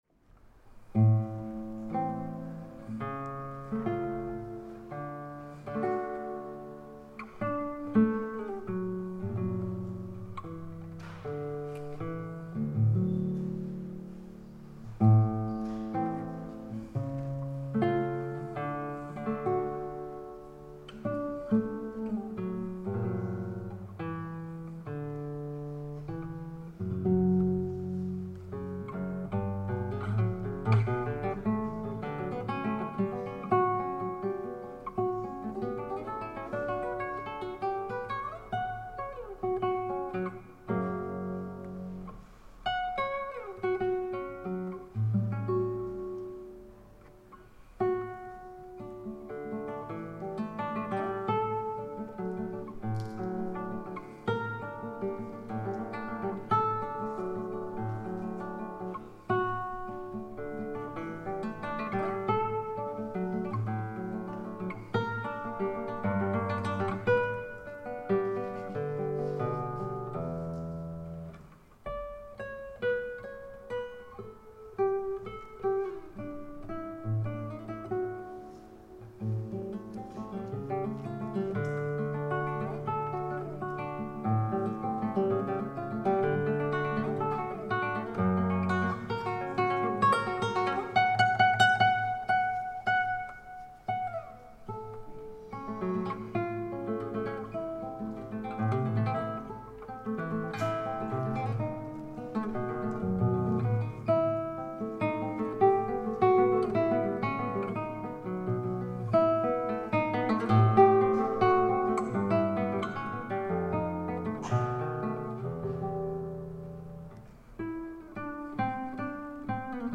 chitarra
Luogo esecuzioneForlì - Prefettura
GenereMusica Classica / Cameristica